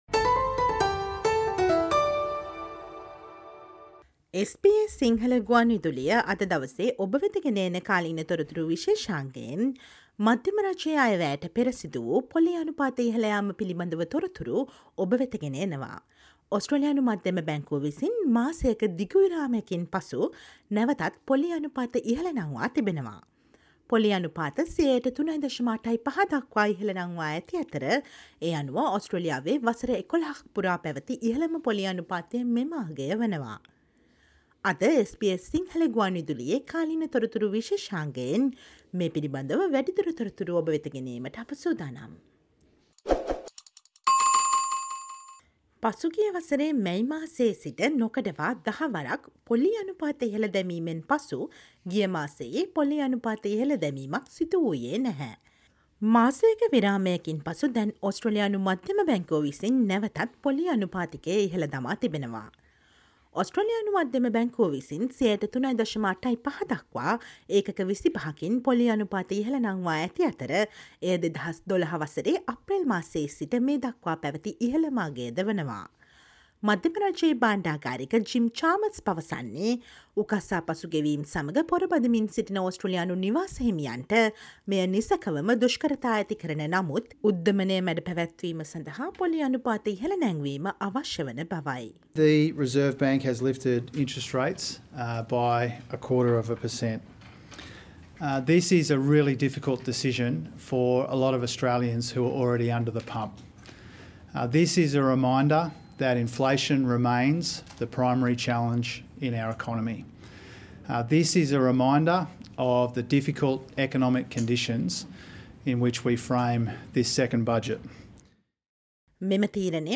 Listen to the SBS Sinhala radio current affair feature on the latest updates on Reserve Bank's interest rates lift after a month-long pause just before the federal budget.